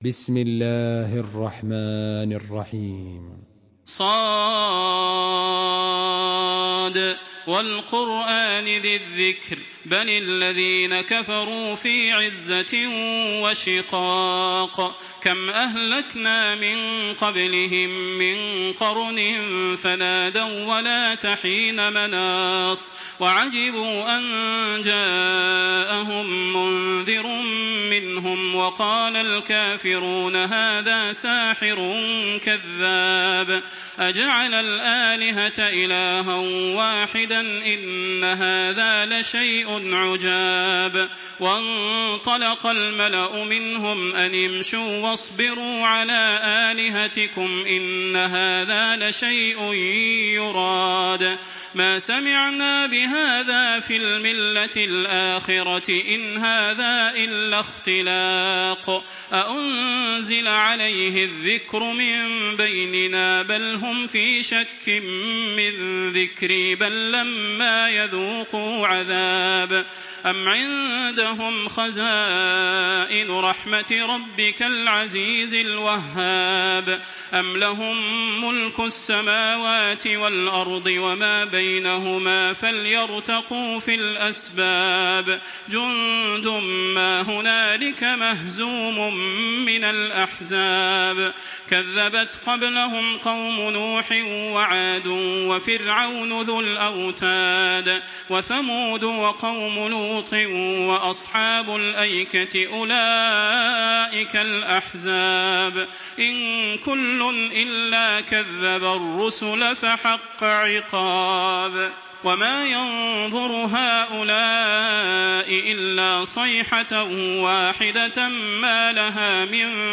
Surah ص MP3 by تراويح الحرم المكي 1426 in حفص عن عاصم narration.
تراويح الحرم المكي 1426
مرتل